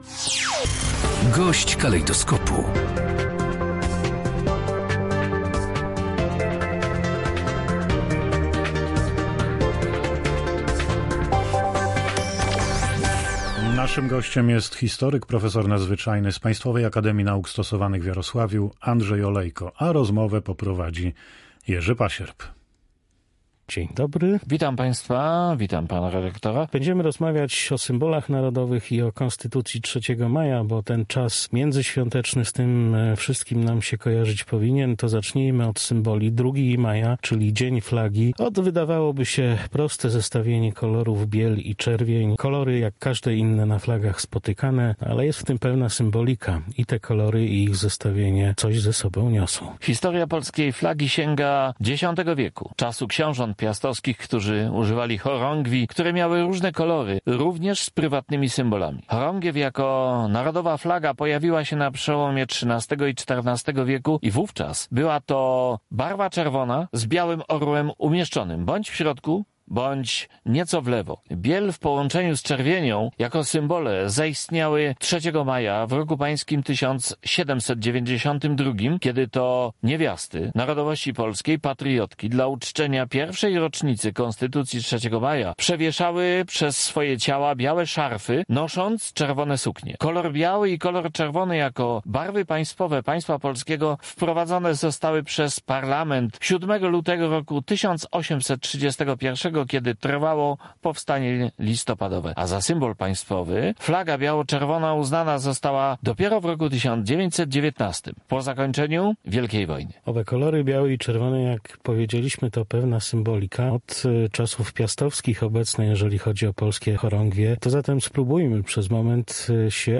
Gość dnia • Dzień Flagi, święto, ustanowione w roku 2004, ma przywoływać historię i znaczenie biało-czerwonej flagi jako jednego z polskich symboli